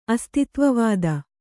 ♪ astitvavāda